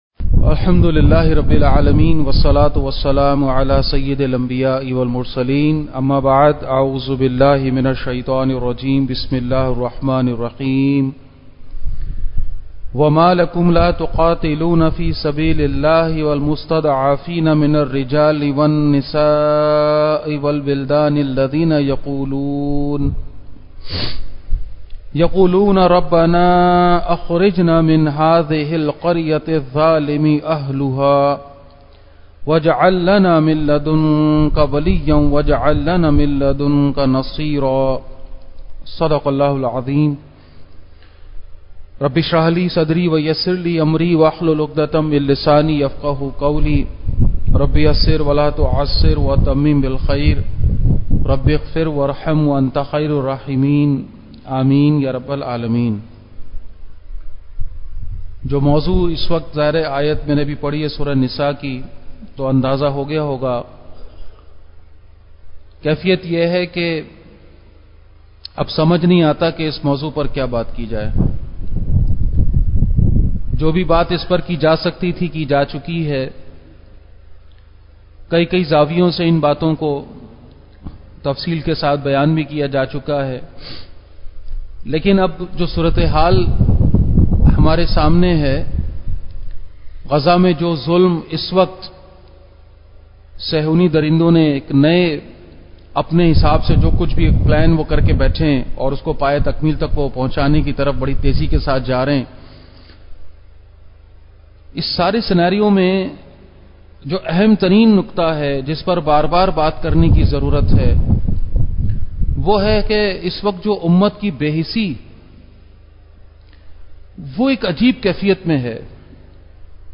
Khutbat-e-Jummah (Friday Sermons)